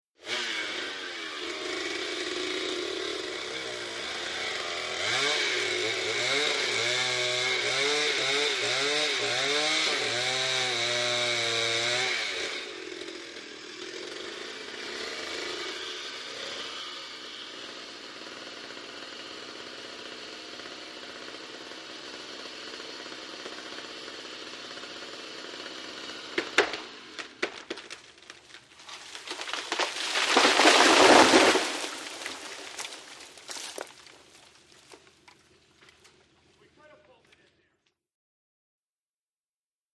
ChainSawCuttTree PE699201
MACHINES - CONSTRUCTION & FACTORY CHAINSAW: EXT: Chain saw cutting, distant, large tree crack fall with foliage movement, voices at end.